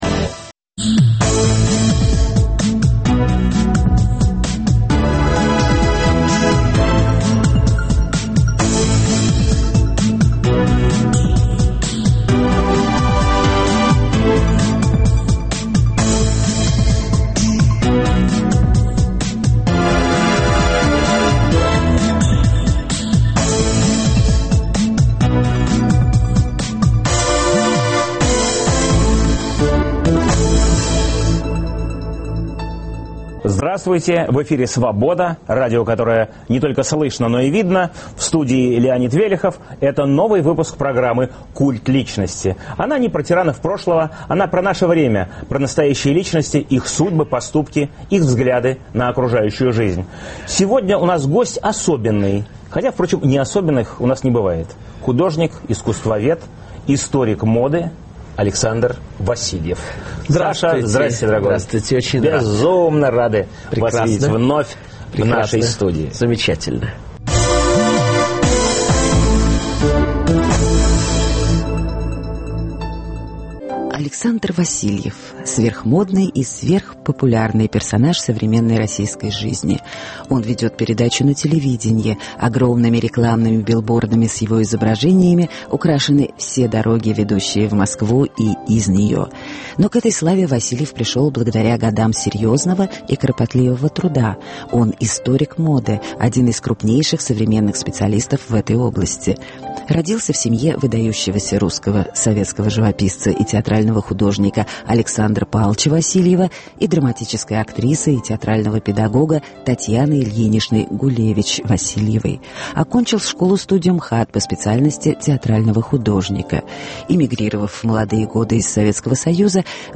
Новый выпуск программы о настоящих личностях, их судьбах, поступках и взглядах на жизнь. В гостях у "Культа личности" историк моды Александр Васильев. Эфир в субботу 7 марта в 18 часов Ведущий - Леонид Велехов.